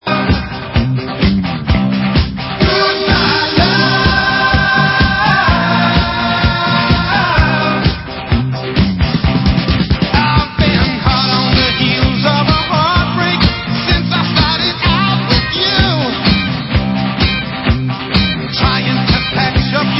• styl: Glam